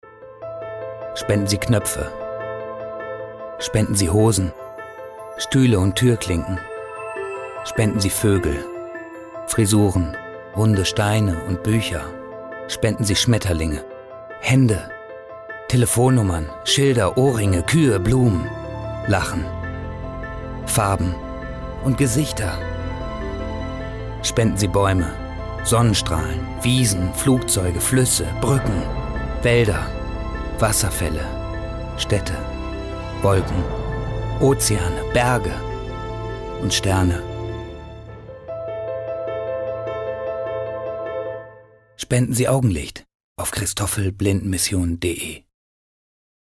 Stimme mit Wiedererkennungswert, großer Flexibilität und Zuverlässigkeit.
Sprechprobe: Sonstiges (Muttersprache):